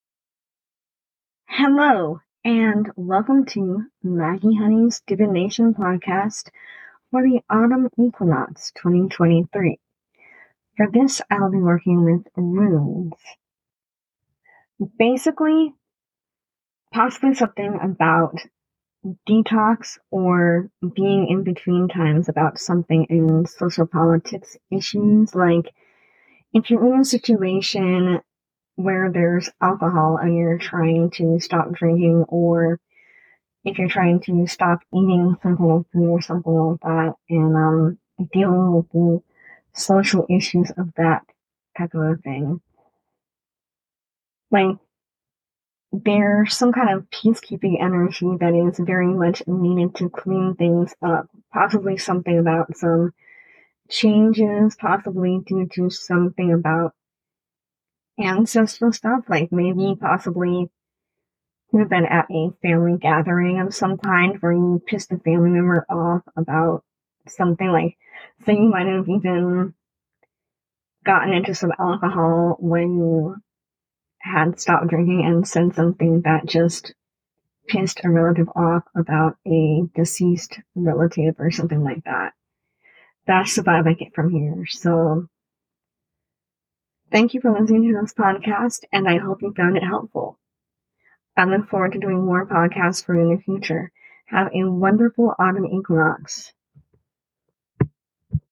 Apologies if I talked too swiftly in some parts of the recording but I just did one take cuz I can usually pick up more intuition when I do it this way. I have been reading runes more intuition based for awhile now as far as interpretation.
You’ll wanna listen to the full reading cuz it’s a bit free flowing.